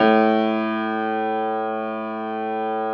53h-pno05-A0.wav